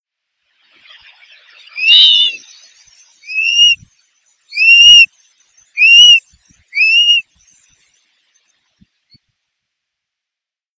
Porzana spilopterus - Burrito plomizo